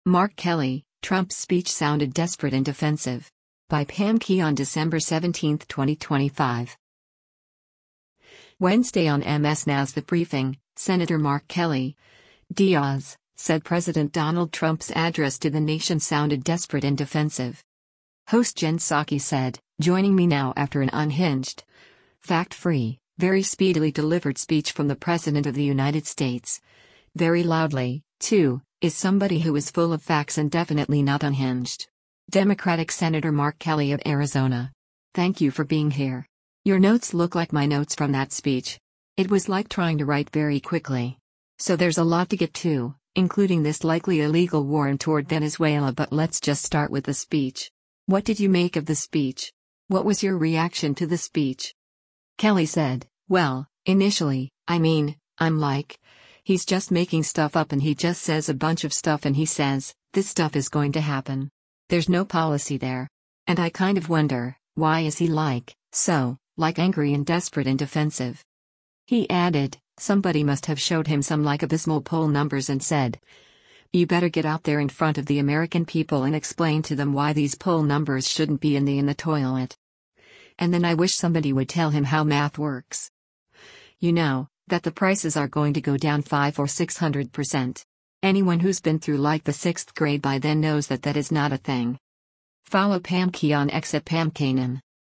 Wednesday on MS NOW’s “The Briefing,” Sen. Mark Kelly (D-AZ) said President Donald Trump’s address to the nation sounded “desperate and defensive.”